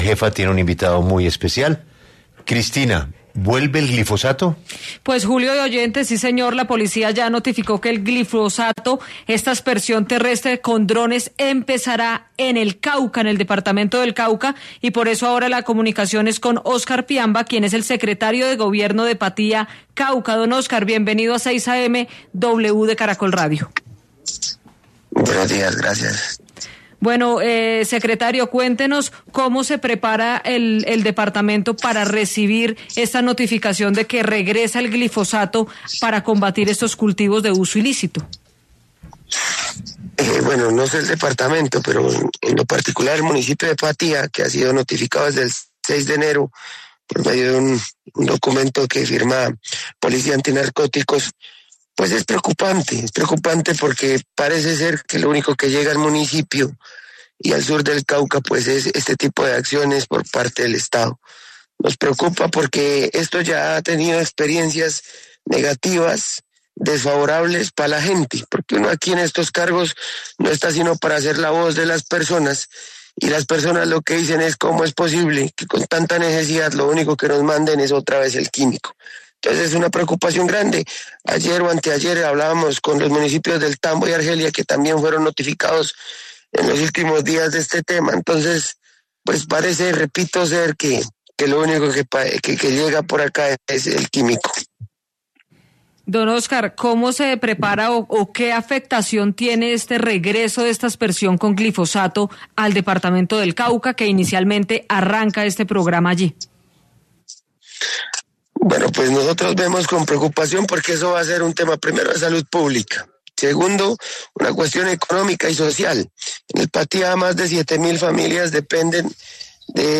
Óscar Piamba, secretario de Gobierno del municipio de Patía, en Cauca, expresó en 6AM W de Caracol Radio la profunda inquietud que esta decisión ha provocado en la región.